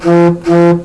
As a result the harmonics become much stronger.
The tone onset is very different from the classical:
1. The oscillation starts with the second harmonic - this might be natural since cup up is very low.
2. There is considearable initial noise - probably because of the turbulent jet flow comes close to or even hits the bridge surface.
3. The tone onset is delayed - the bridge would attract the jet by the Coanda effect and distract if from hitting the labium until a sufficiently large disturbance occurs.